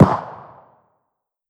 ACE3 / extras / assets / CookoffSounds / shotshell / far_1.wav